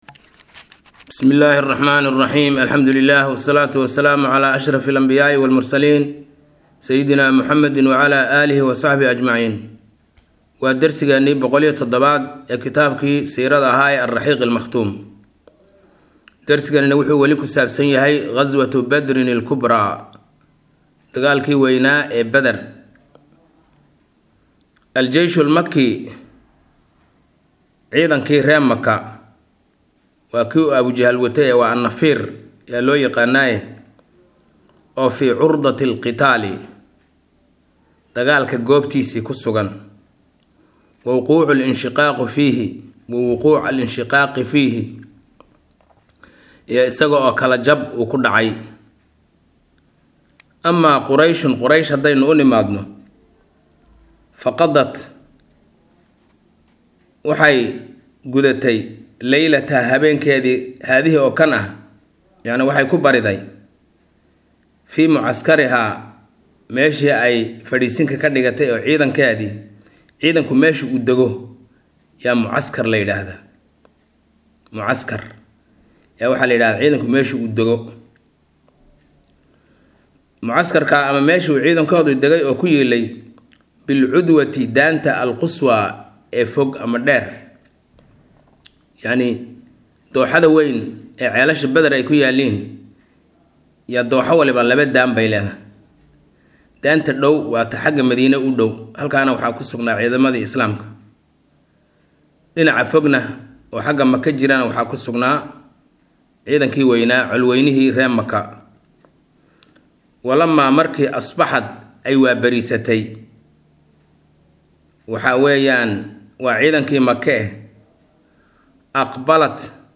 Maqal– Raxiiqul Makhtuum – Casharka 107aad